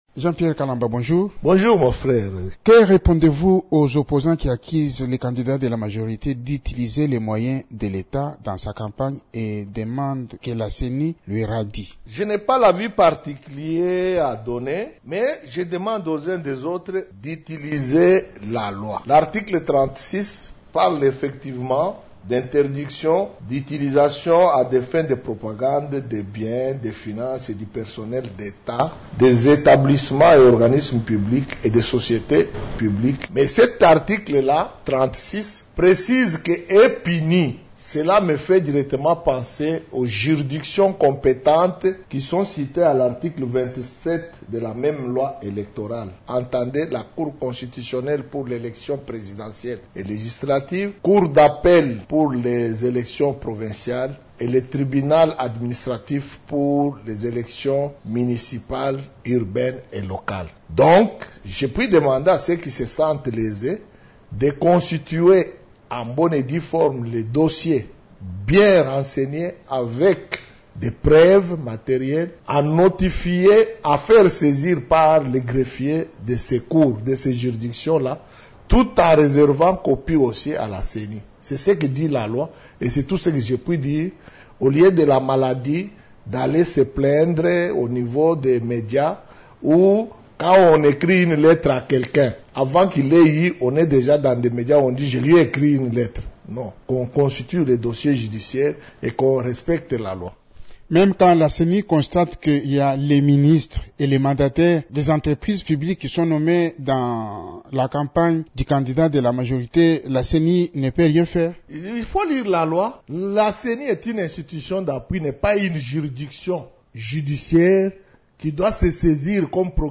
Le rapporteur de la CENI s’entretient avec